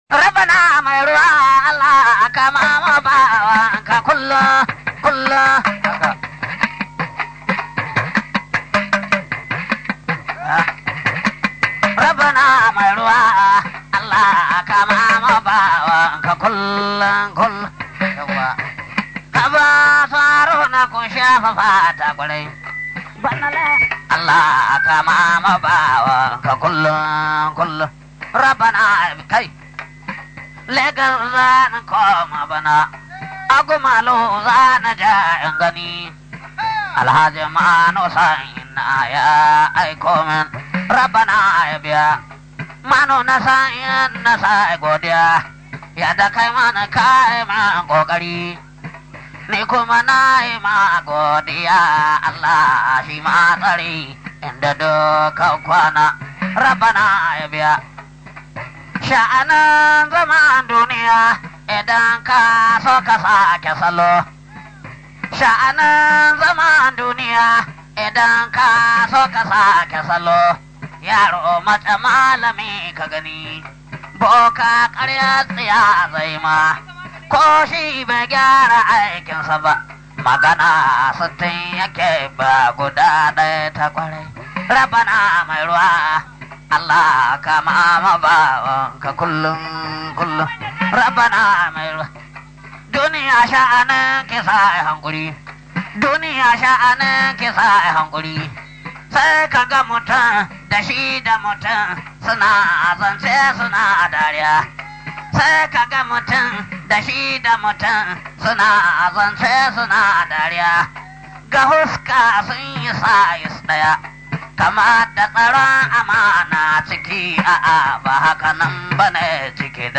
Wakokin Gargajiya